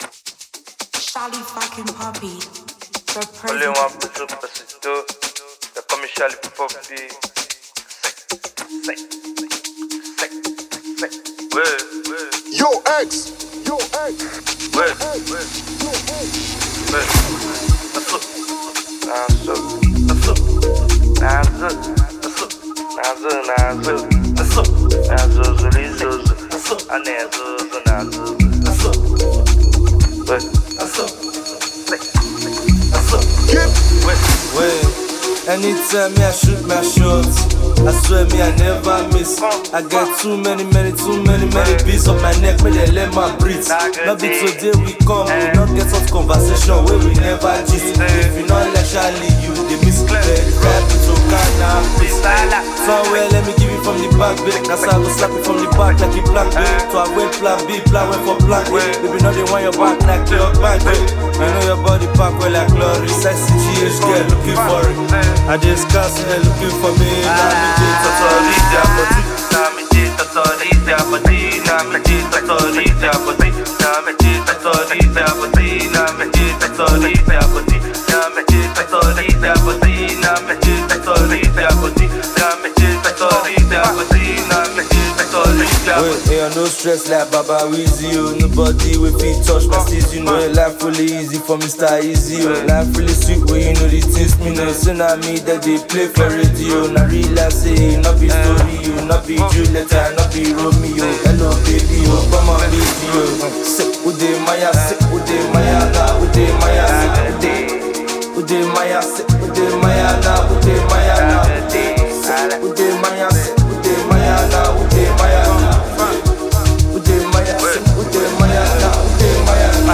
Afrobeats
The music scene is excited to embrace this energetic release